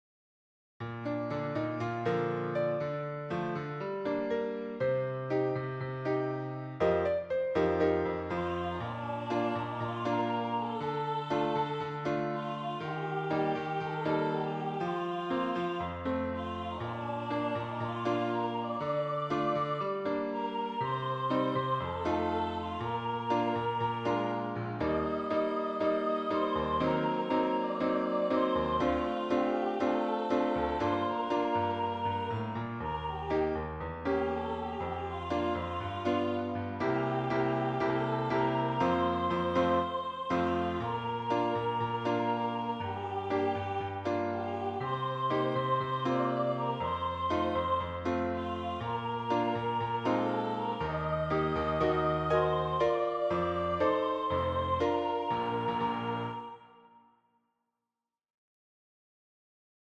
メロディ